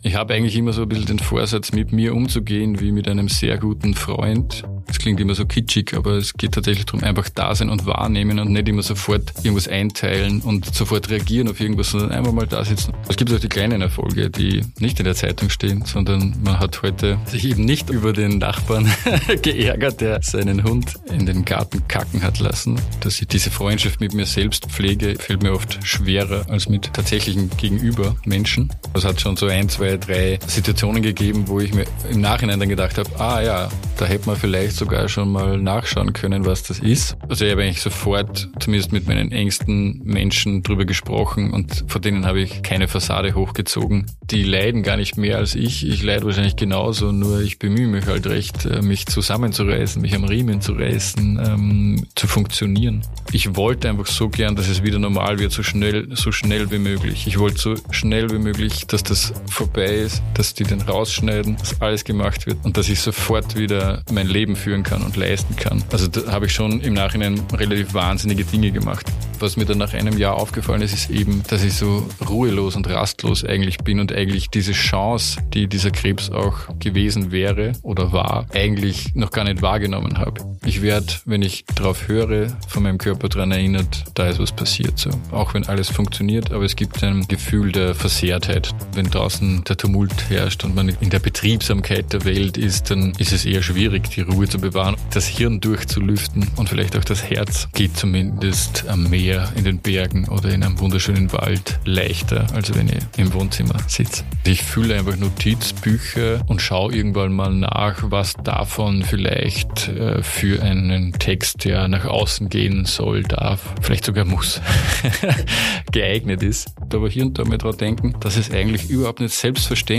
Gespräch) · Folge 21 ~ Männerkrebs – Was tut Mann mit Krebs?